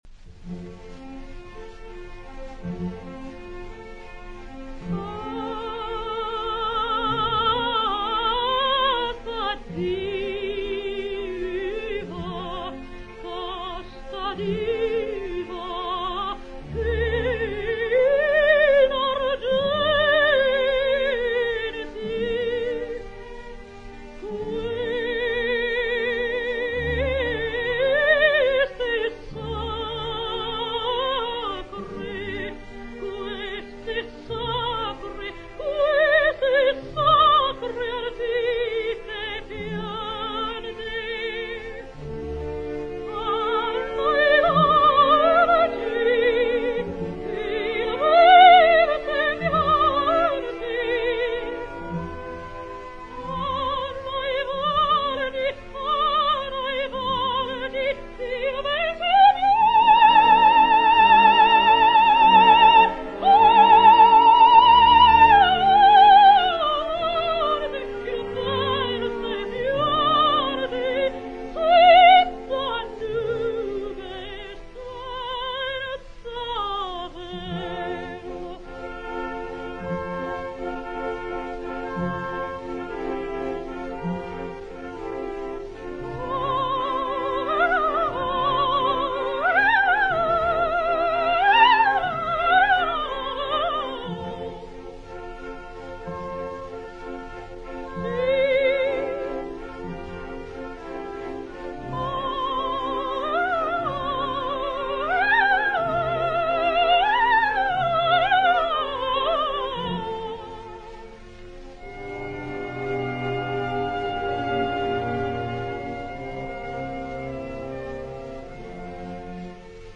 Encore plus rarement papa va chercher au grenier la valise de l’antique gramophone à manivelle et les quelques 78 tours qui ont sa faveur.
Ça gratte et  dérape bien davantage encore que lorsque papa est au violon, mais c’est magique, et aujourd’hui encore je ne peux écouter
ninon_vallin-casta_diva-norma.mp3